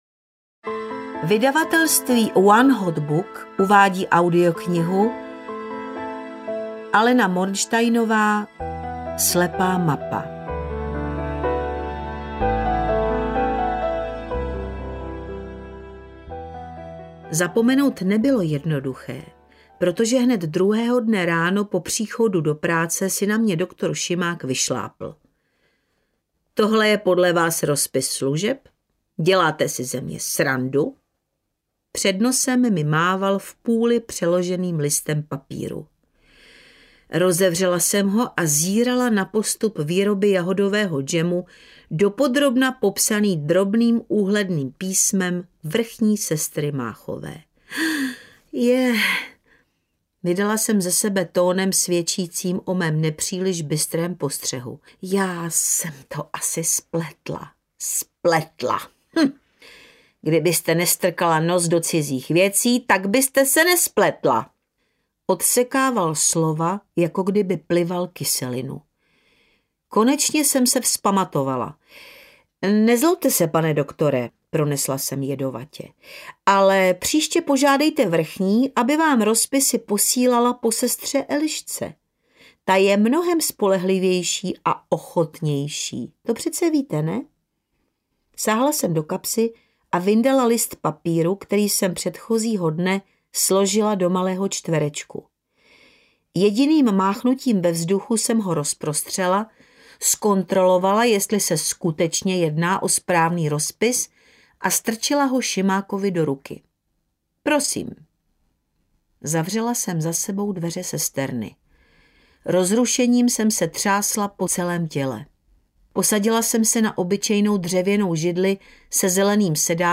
Slepá mapa audiokniha
Ukázka z knihy
• InterpretVeronika Gajerová